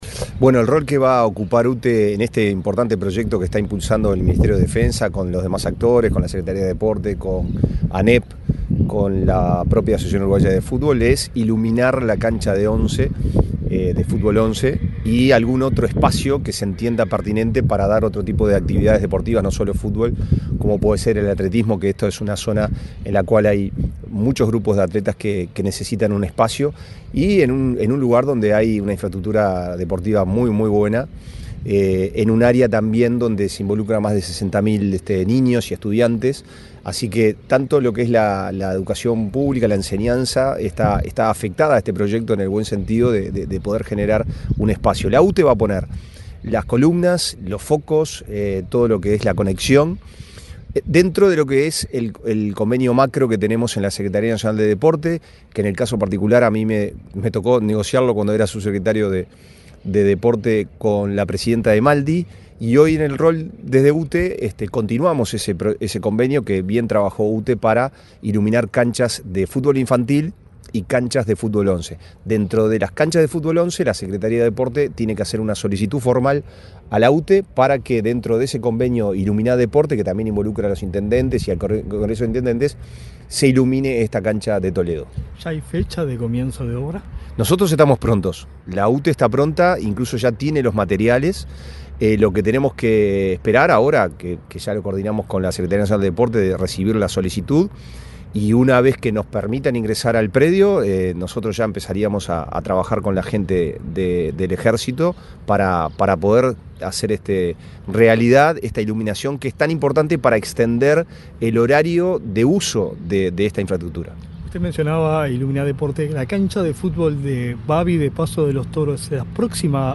Declaraciones del vicepresidentdode UTE, Pablo Ferrari